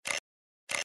Звуки клика мышкой
На этой странице собраны звуки кликов компьютерной мыши — от одиночных щелчков до быстрых последовательностей.